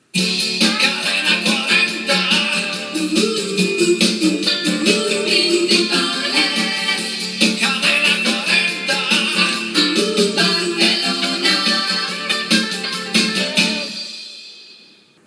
Dos indicatius de la ràdio